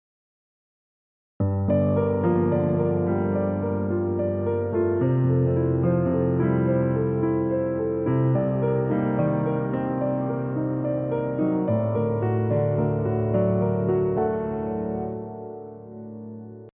piano 3 Minutes
I have a nice progression for you, it goes like this: Gm7 — F — Cm7 — D+.
Gm and Cm in my progression get 8-to-the-bar bass, F and D+ are being interpreted with broken 10ths.
I chose to use arpeggiated shell chord voicings as a right hand line, alternating between 7-3-5 and 3-7-9 variations. In my opinion, these two patterns (8-to-the-bar and broken 10ths) fit very well together, as they fill a really huge frequency range, thus creating a very spacious and wide sounding bass line.